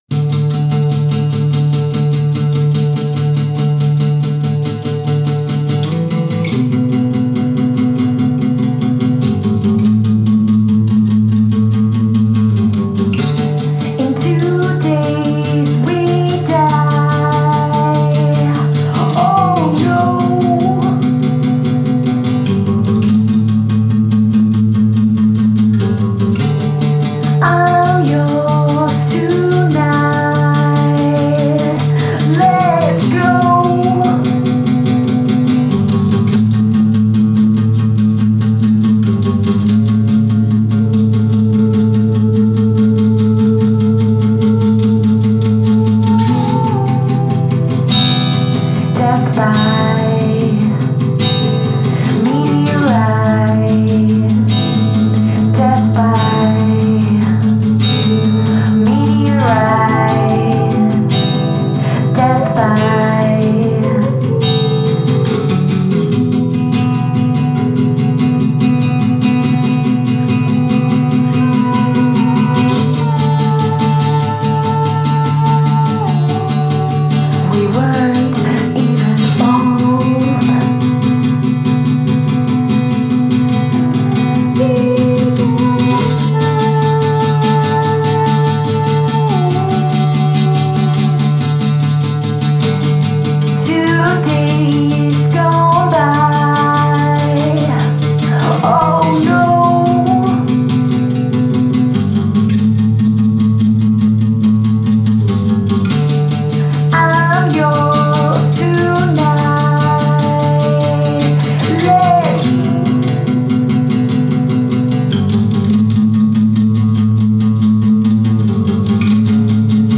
Even the whistling, the effing whistling.